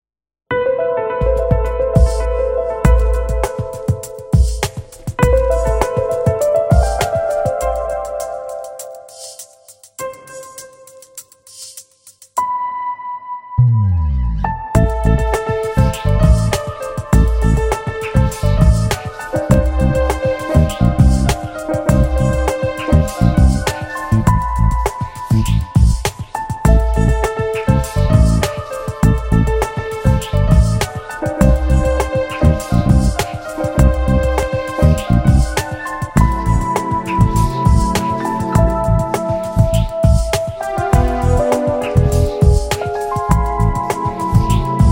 Chill Out / Novas Músicas